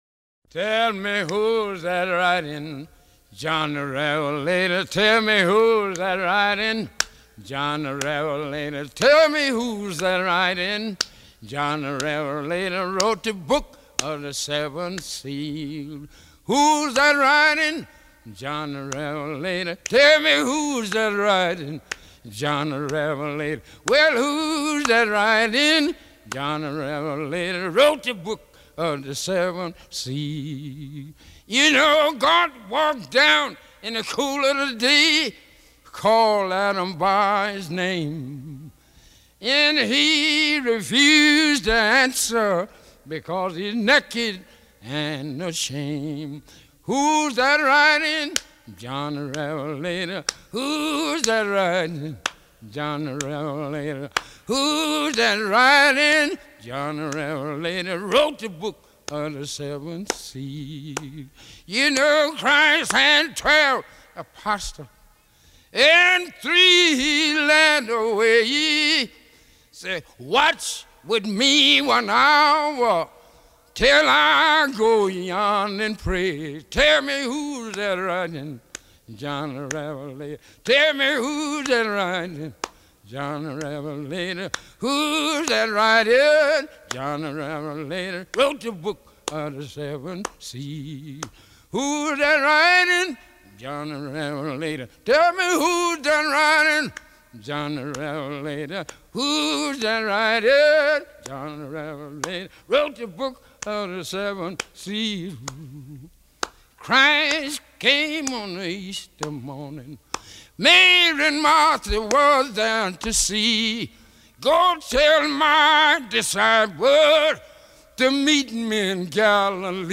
Highway 61 הבלוז של הדלתא מנוגן (משנת 1920 בקירוב) כמעט לעולם רק בגיטרה אקוסטית (רגילה או מברזל, עם או בלי סלייד) ומפוחית, ומלווה בשירה באריטונית אופיינית.
השיר הספיריטואלי הזה הוא ממש החיבור של הבלוז לשורשים הרוחניים שלו.